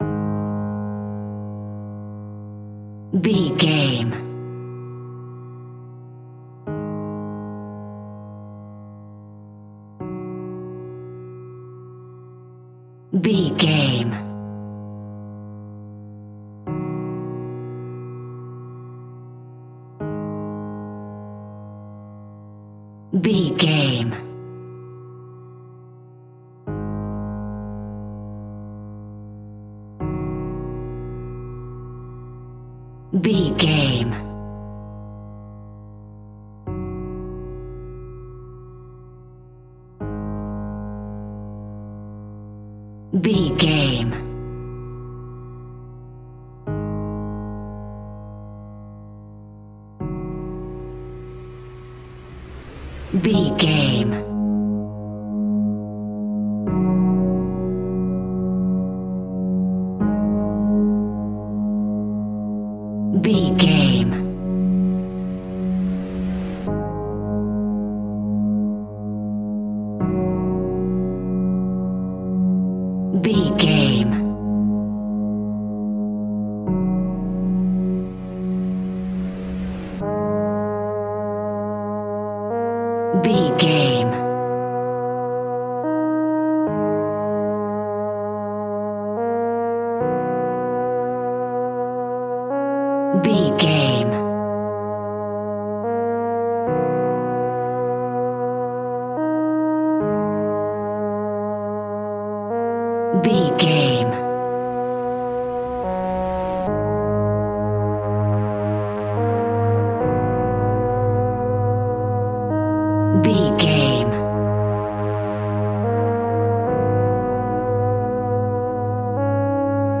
Aeolian/Minor
A♭
scary
ominous
dark
haunting
eerie
piano
synthesizer
horror music
Horror Pads